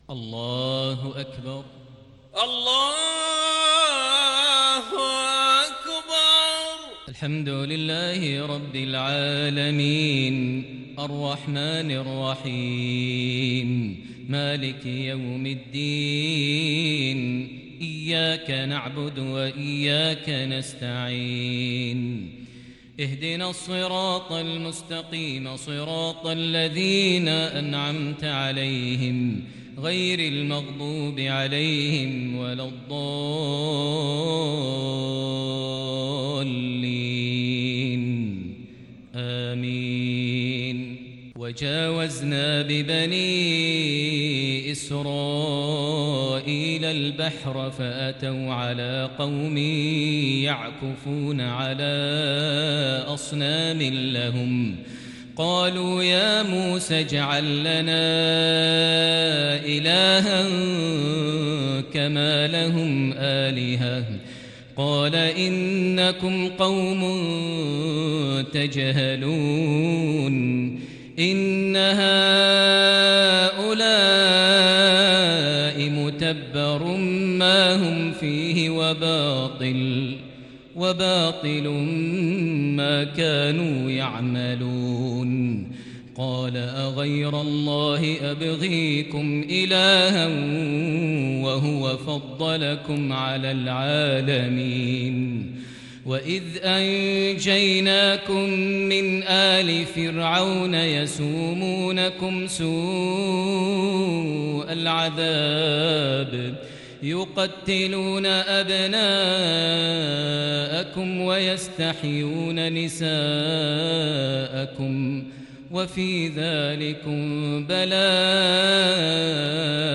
صلاة العشاء للشيخ ماهر المعيقلي 22 صفر 1442 هـ
تِلَاوَات الْحَرَمَيْن .